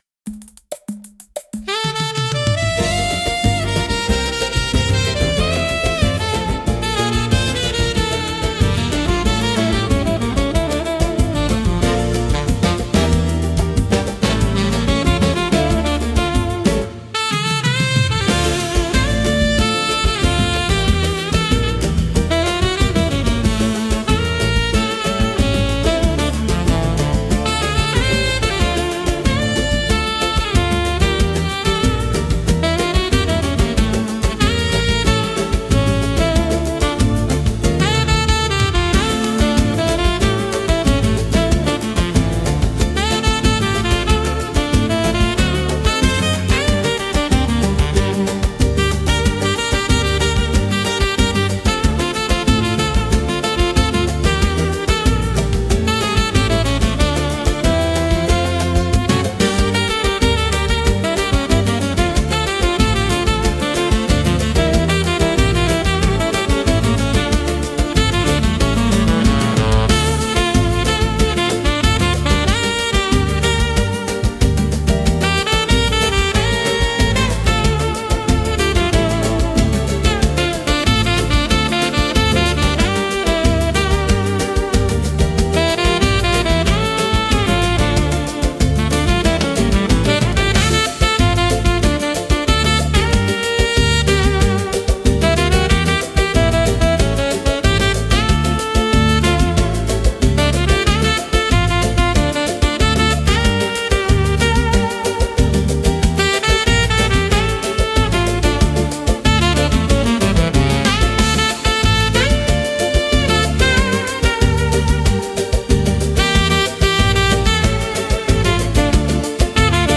IA: música e arranjo) instrumental 6